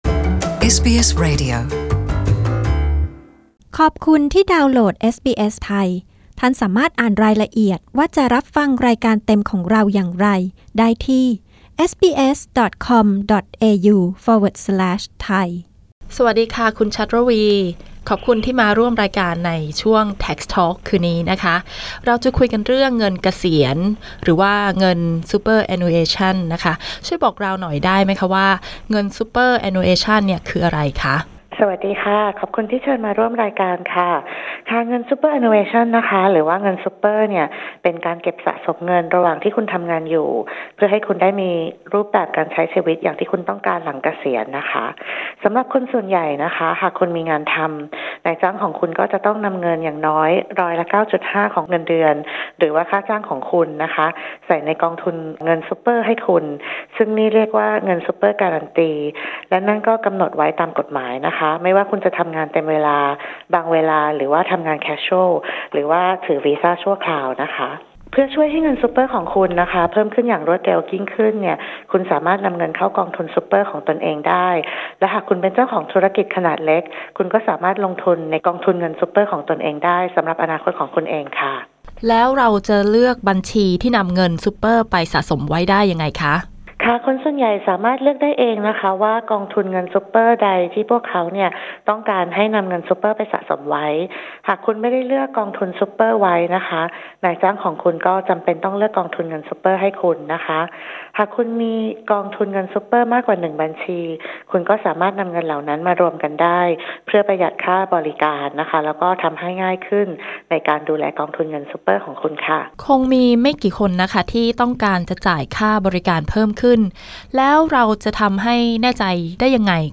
ฟังรายละเอียดจากเจ้าหน้าที่สำนักงานสรรพากรออสเตรเลียเรื่องเงินซูเปอร์แอนนูเอชันหรือเงินซูเปอร์ ซึ่งเป็นการเก็บสะสมเงินระหว่างที่คุณทำงานอยู่ เพื่อให้คุณได้มีรูปแบบการใช้ชีวิตอย่างที่คุณต้องการหลังเกษียณ